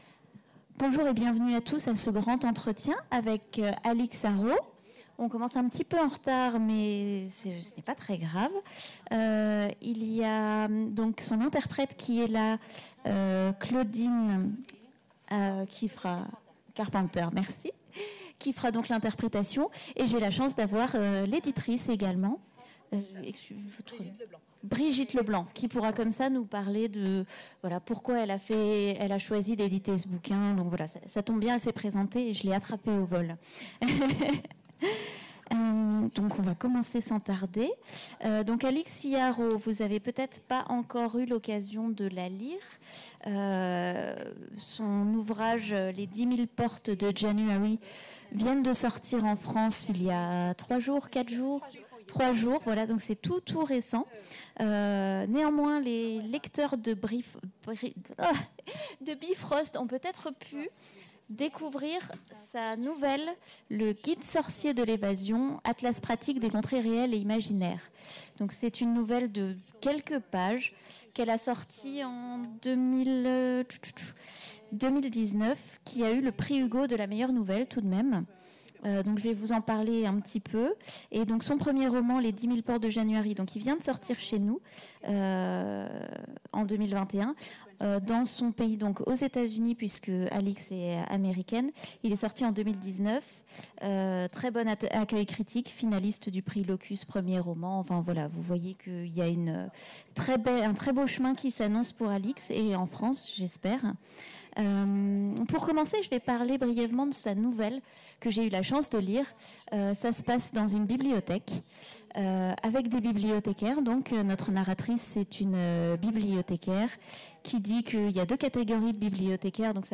A l'occasion des Imaginales 2021, redécouvrez le Grand Entretien d'Alix E. Harrow.